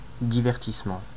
Ääntäminen
France Métropolitaine: IPA: /di.vɛʁ.tis.mɑ̃/